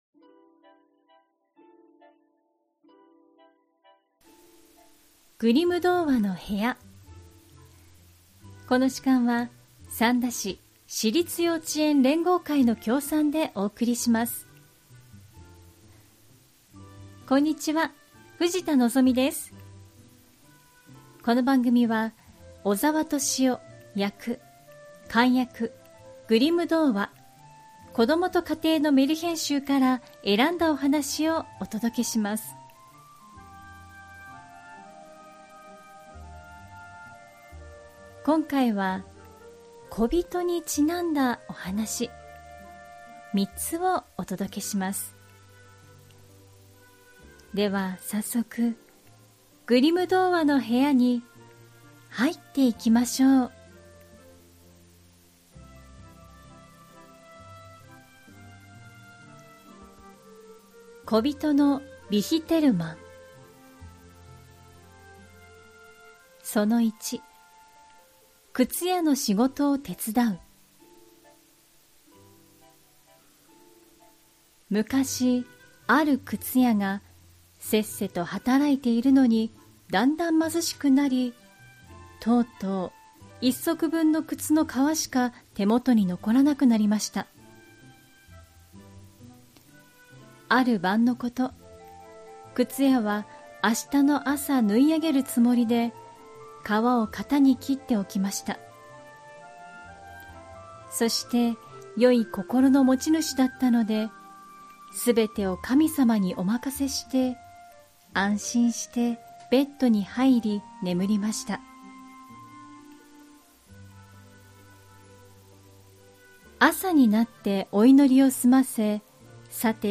グリム兄弟によって集められたメルヒェン（昔話）を、翻訳そのままに読み聞かせします📖 今回お届けするのは『こびとのビヒテルマン』。 「小人の靴屋」といったタイトルで知られる「靴屋の仕事を手伝う」のほか、「お手伝いさんに名づけ親になってもらう」「子どもをとりかえる」、短い3篇のお話です。